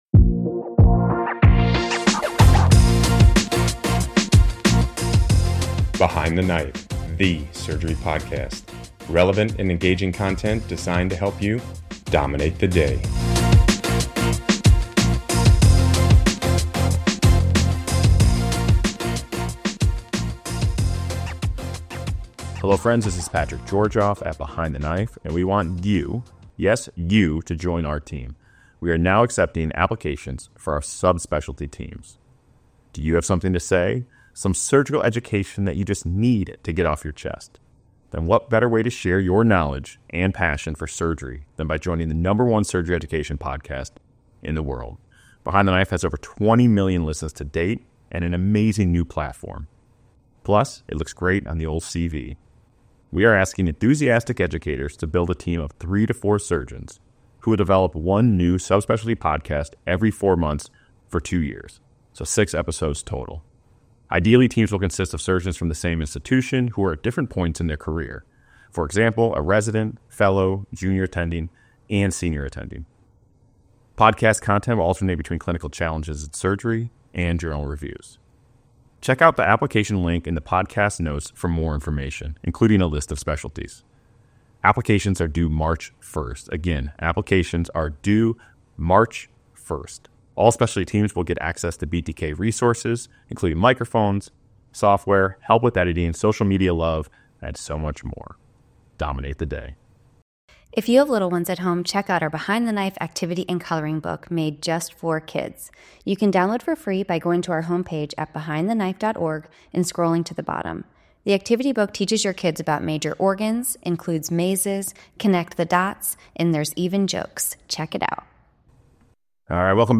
The speakers are surgeons at different career stages (residents, fellows, attendings) and they share tips and tricks for maintaining relationships with significant others, children, parents, and friends.